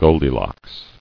[gold·i·locks]